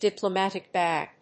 アクセントdiplomátic bág
音節diplomàtic bág